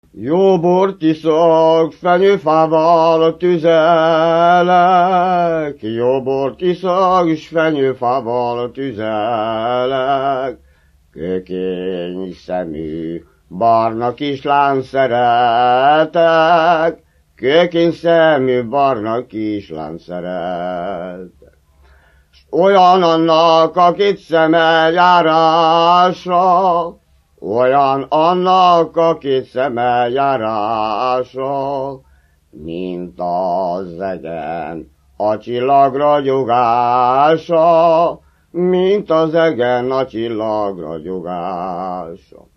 Erdély - Alsó-Fehér vm. - Magyarszentbenedek
ének
Műfaj: Lassú csárdás
Stílus: 6. Duda-kanász mulattató stílus
Kadencia: 8 (5) 7 1